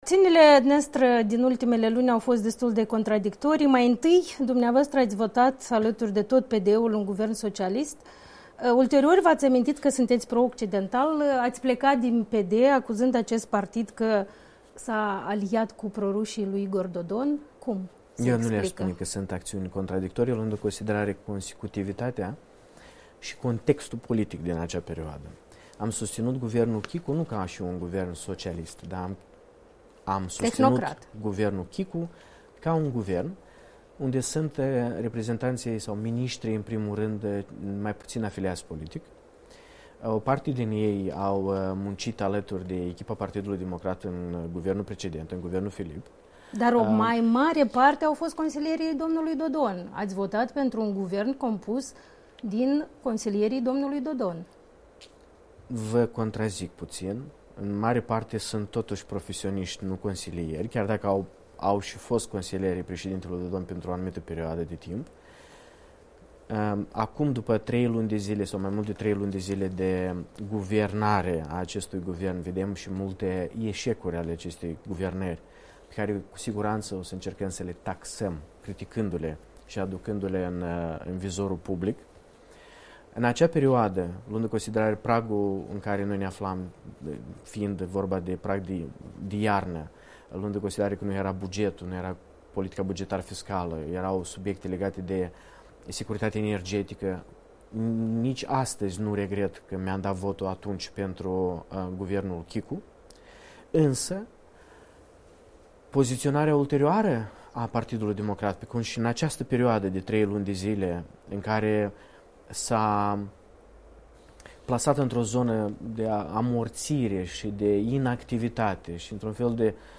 Interviu Andrian Candu 4 martie 2020